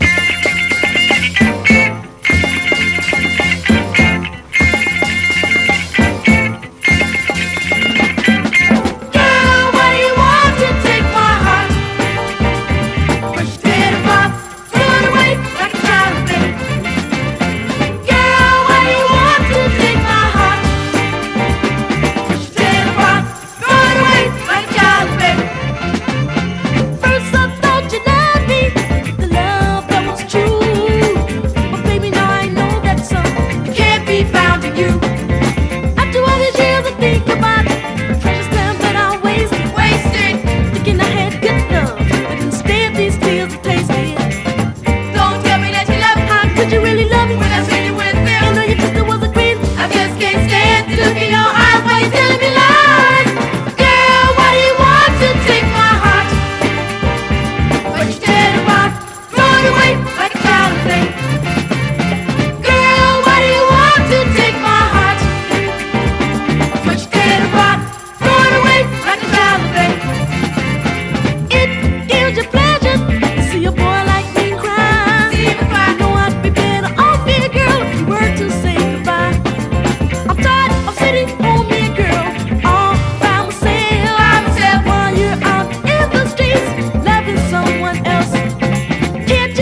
SOUL / SOUL / FREE SOUL / KIDS SOUL